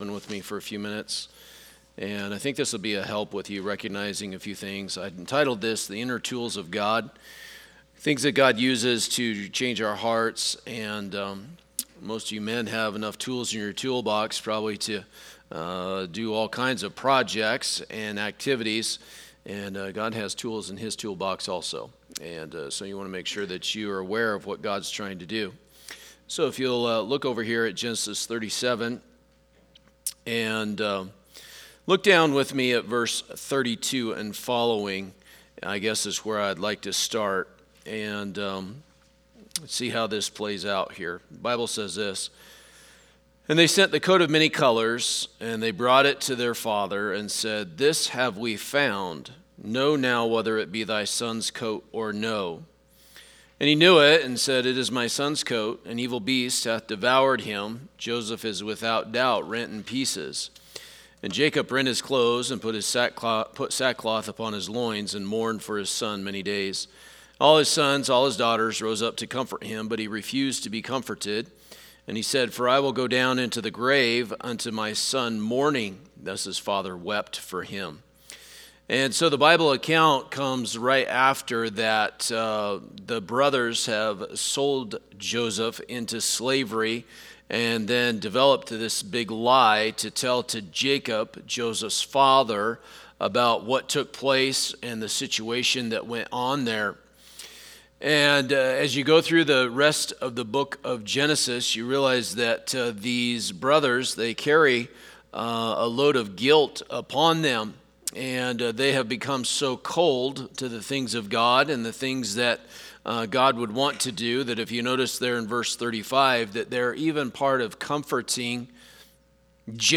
Passage: Genesis 37 Service Type: Midweek Service